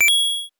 coin_9.wav